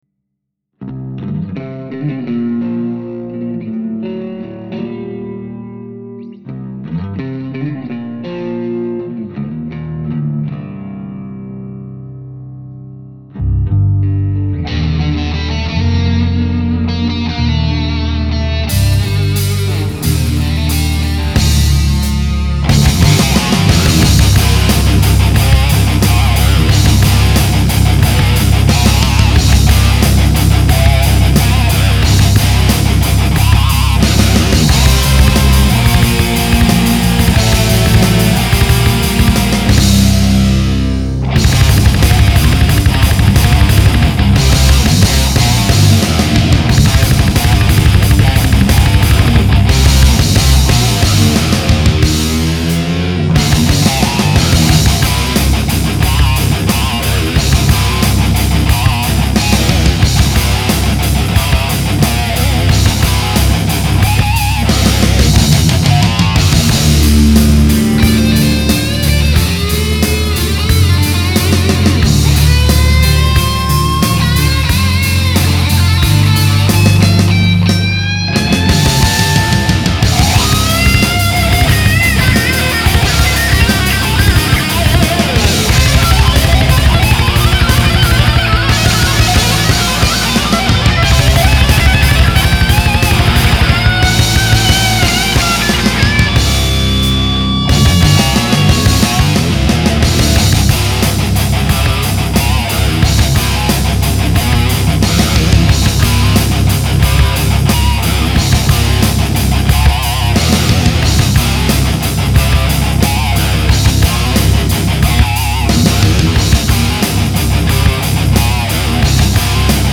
Song MP3